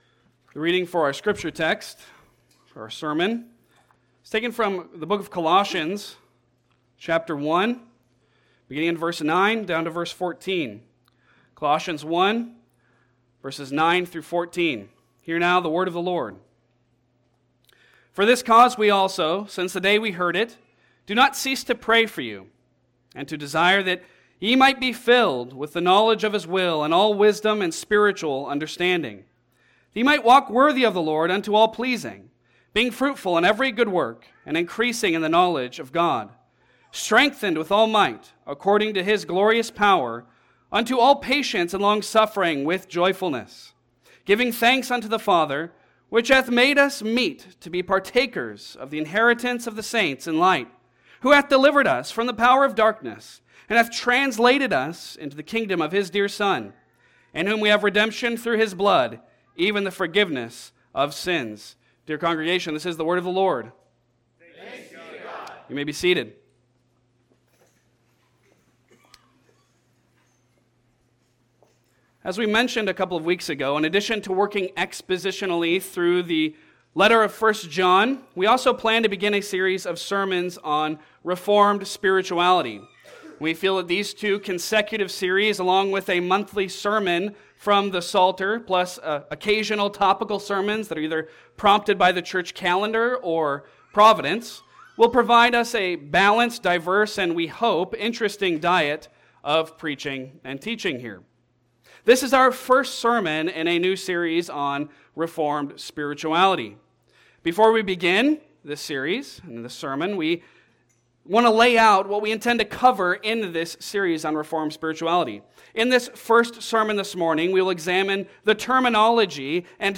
Passage: Colossians 1:9-14 Service Type: Sunday Sermon